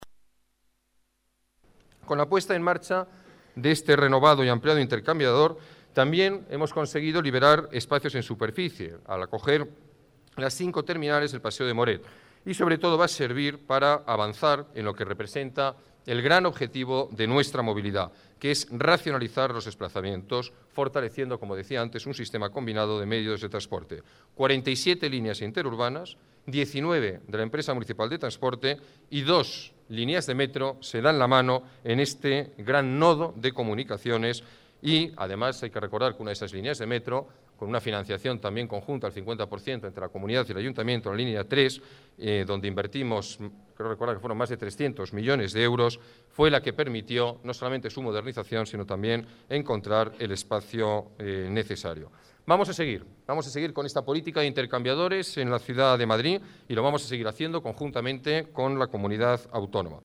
Nueva ventana:Declaraciones del alcalde: datos de la ampliación del intercambiador de Moncloa